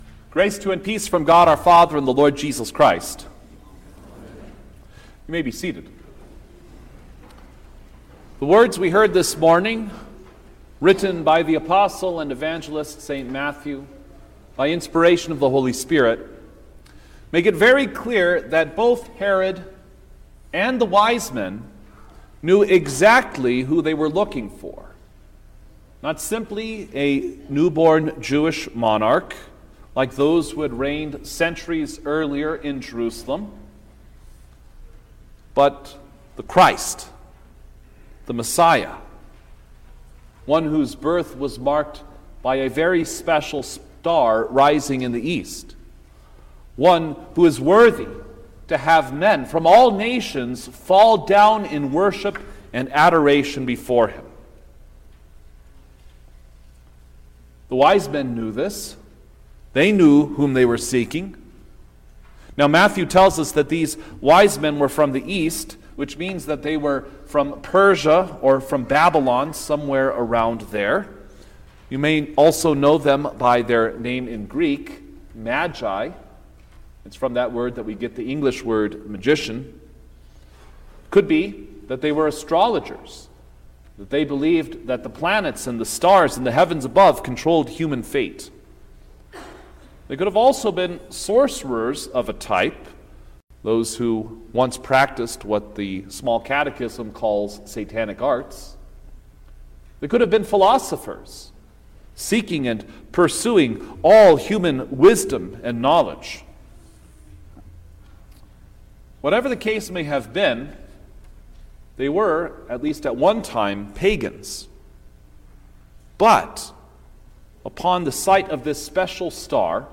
January-5_2025_The-Epiphany-of-Our-Lord_Sermon-Stereo.mp3